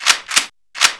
shake_sound_male.wav